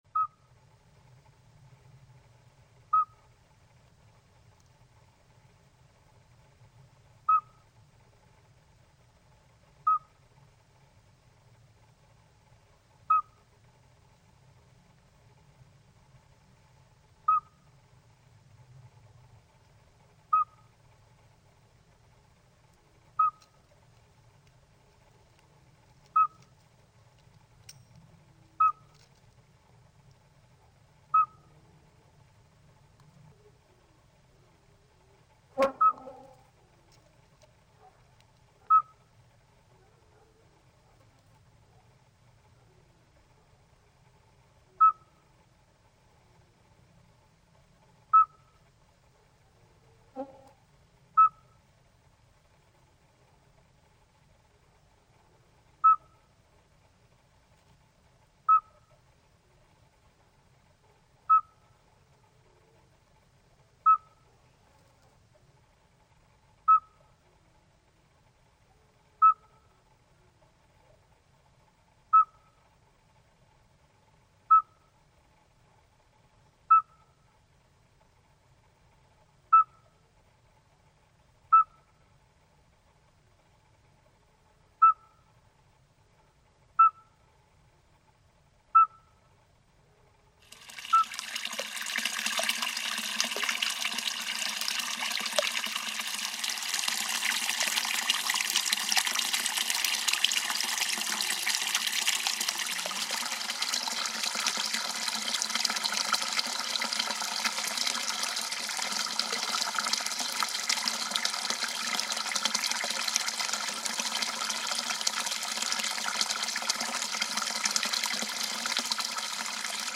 Dans les marronniers de la petite place, ce sont les "sourdons" qui font entendre leurs petits appels discrets.